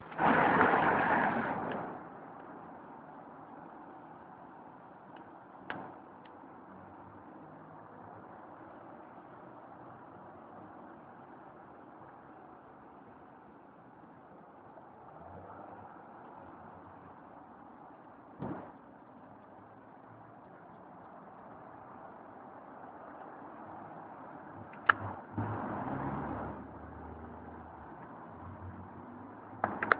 Bruits de voitures